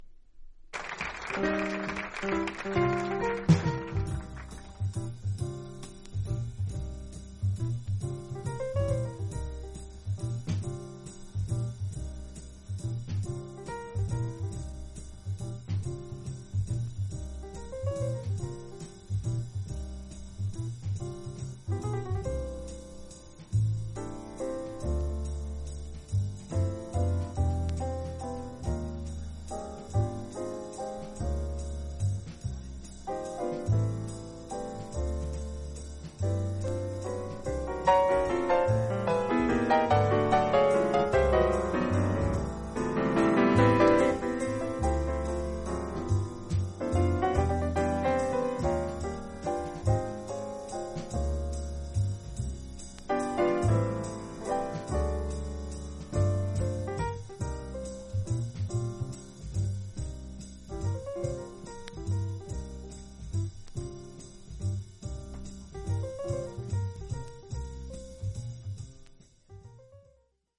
ジャズ
実際のレコードからのサンプル↓